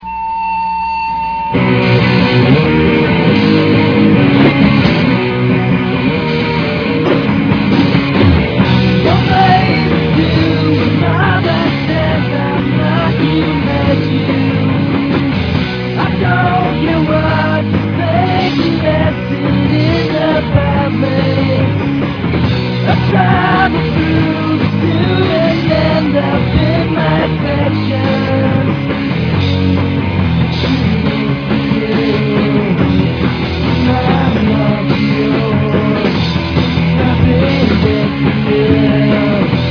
During the fall the band did some 4-track/boombox demos.
Here are some sound samples from the fall '90 4-track demos: